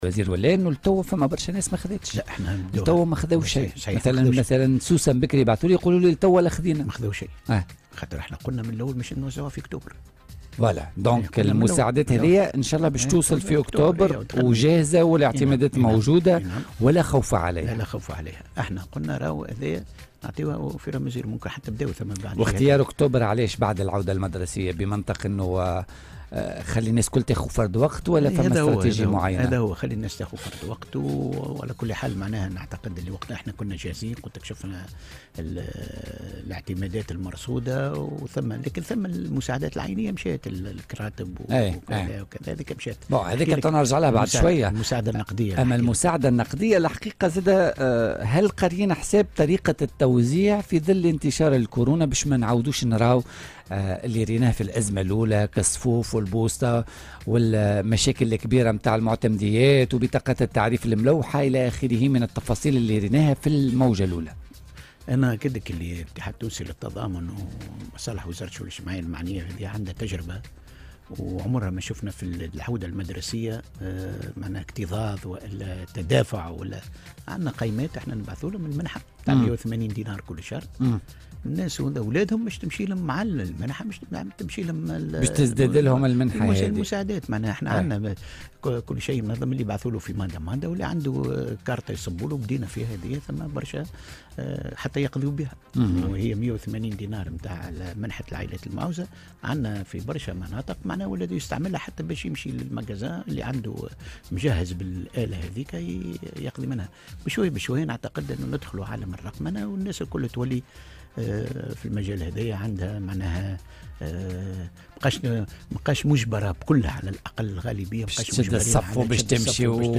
وأضاف اليوم في مداخلة له على "الجوهرة أف أم" أنه سيتم صرف هذه المساعدات مع المنحة الشهرية التي تنتفع بها العائلات المعوزة.